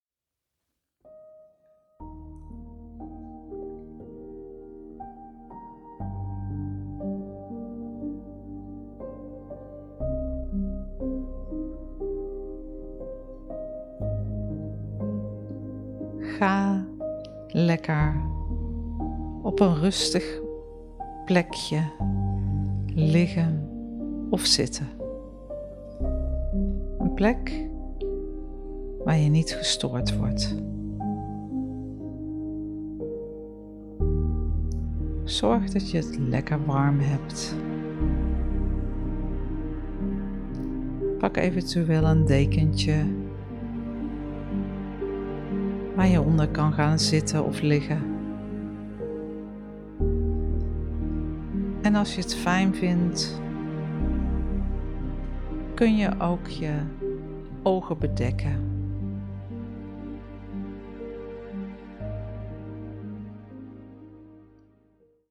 bodyscan meditatie
Bodyscan-meditatie-teaser.mp3